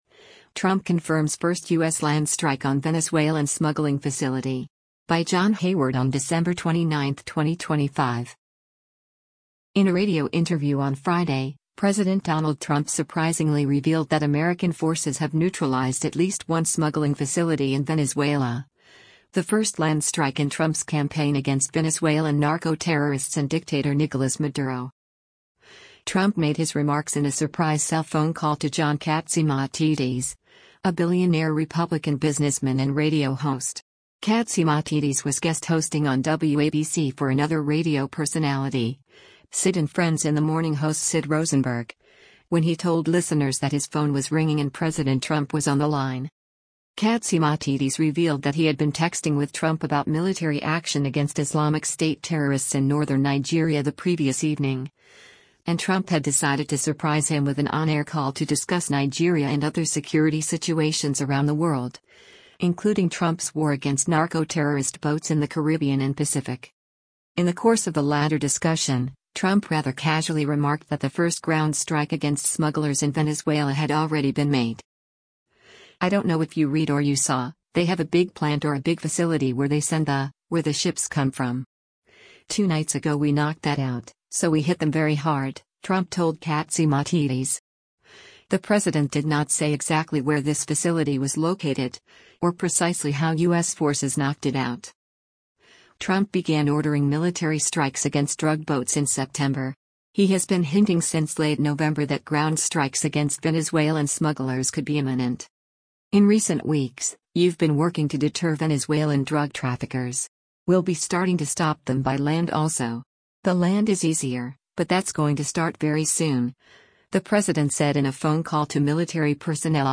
In a radio interview on Friday, President Donald Trump surprisingly revealed that American forces have neutralized at least one smuggling facility in Venezuela, the first land strike in Trump’s campaign against Venezuelan narco-terrorists and dictator Nicolas Maduro.
Trump made his remarks in a surprise cell phone call to John Catsimatidis, a billionaire Republican businessman and radio host.